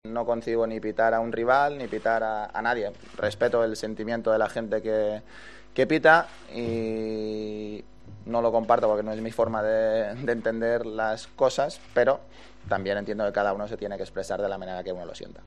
El tenista balear atendió a los medios de comunicación a su llegada al Conde de Godó y señaló que no concibe pitar "ni a un rival ni a nadie" en relación a los pitos al himno nacional del pasado sábado en la Final de la Copa del Rey pero entiende que "cada uno se tiene que se tiene que expresar de la forma que mejor lo sienta".